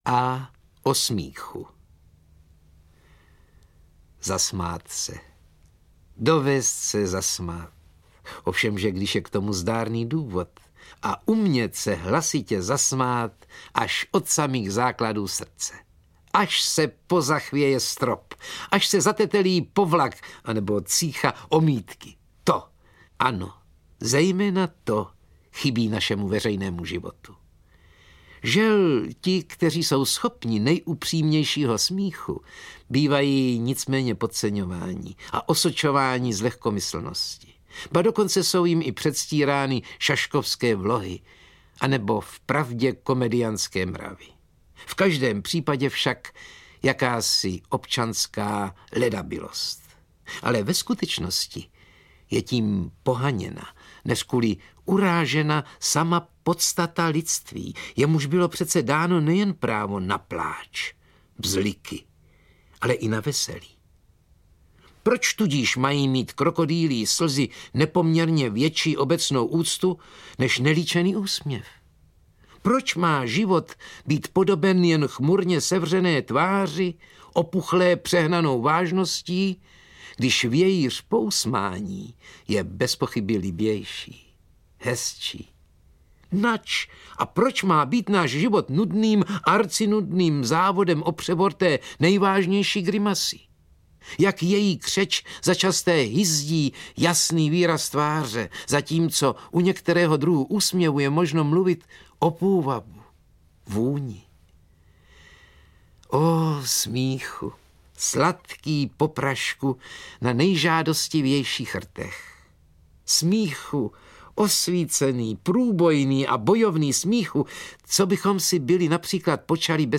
Úsměvy Karla Konráda audiokniha
Ukázka z knihy
• InterpretMartin Růžek, Vlastimil Brodský, Miloš Kopecký